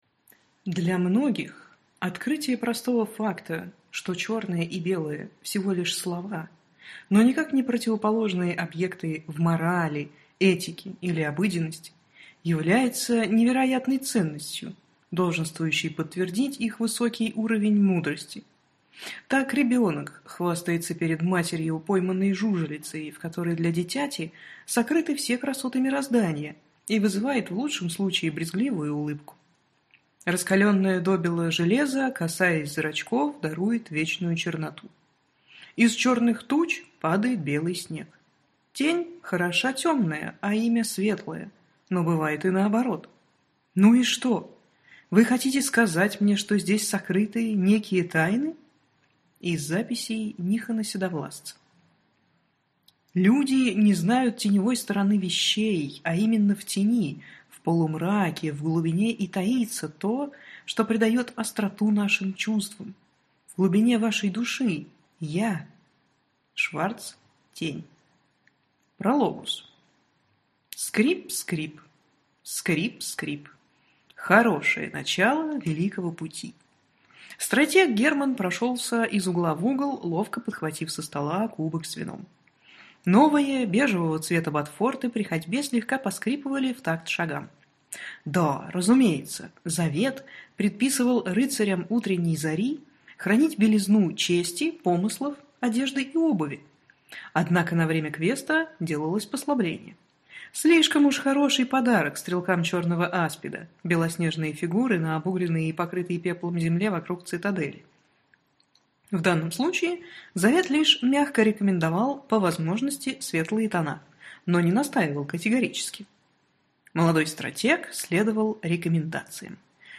Аудиокнига Приют героев | Библиотека аудиокниг